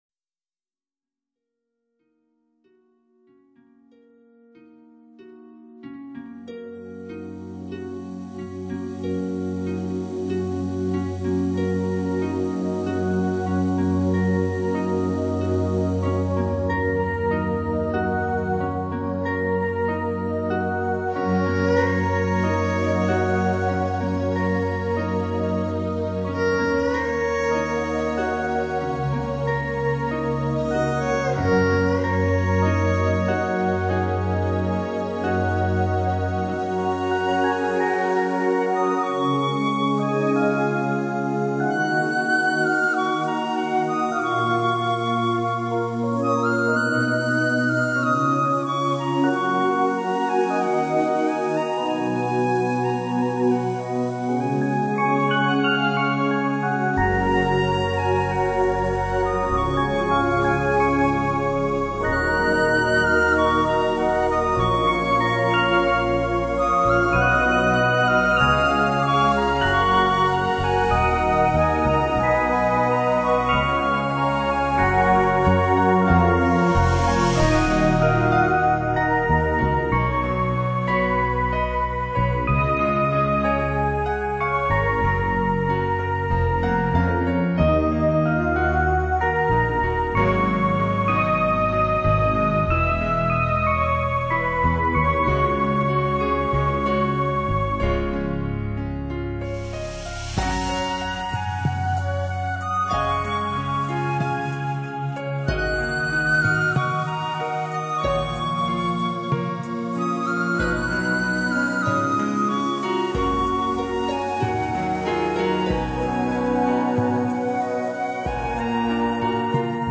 长笛、键盘
钢琴
塔布拉鼓、打击乐